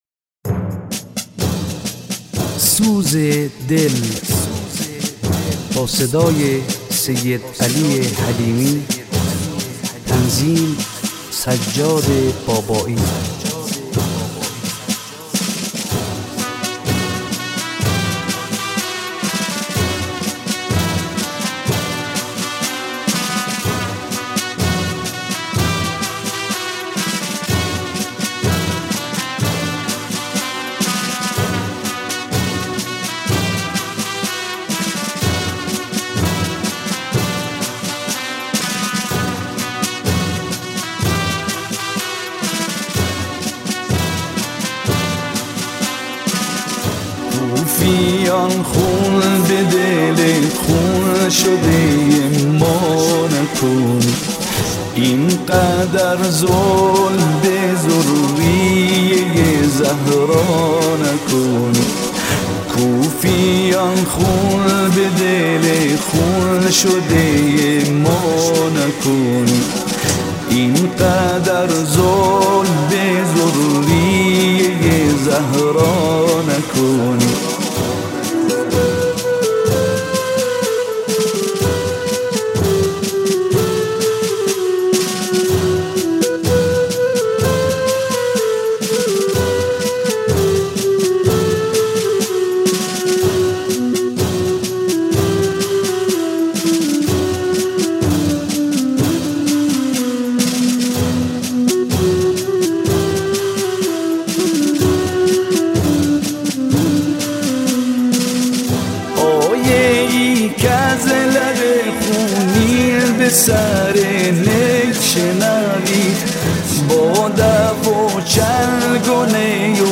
مداحی؛ سوز دل